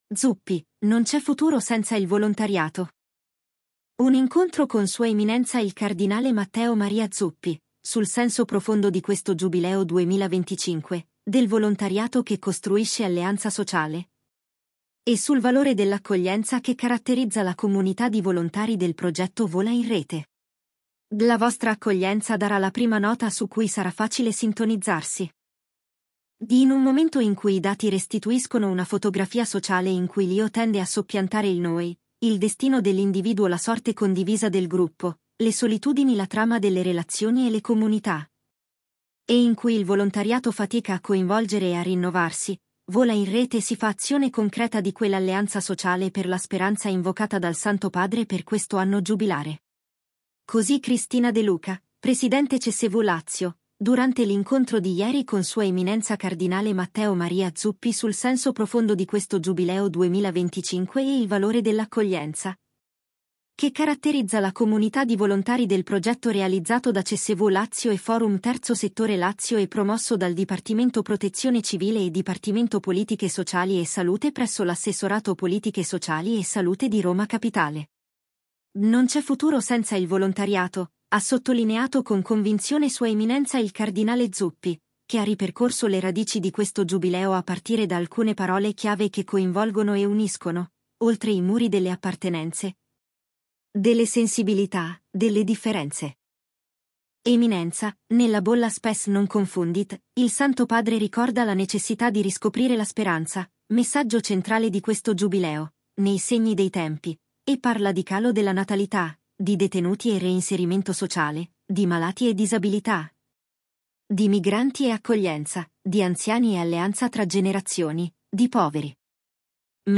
Intervista-SE-Cardinale-Zuppi-Realizzato-con-Clipchamp
Intervista-SE-Cardinale-Zuppi-Realizzato-con-Clipchamp.mp3